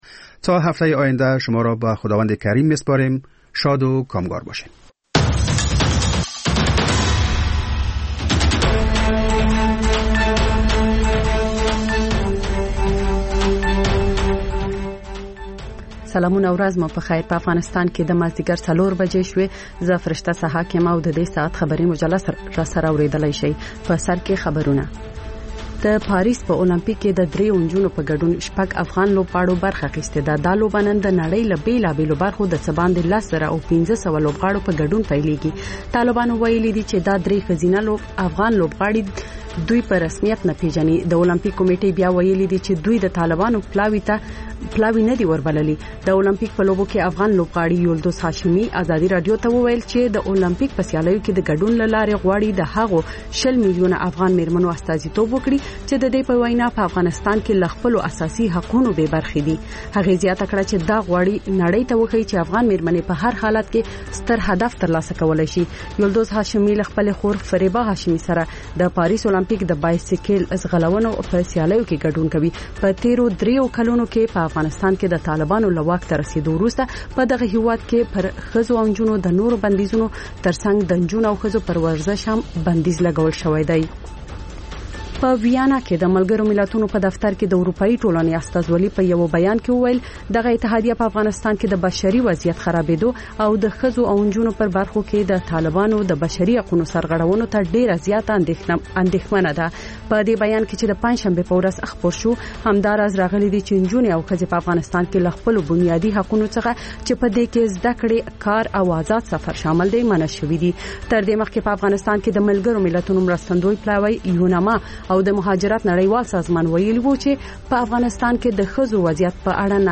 مازیګرنی خبري ساعت - P1 سټوډیو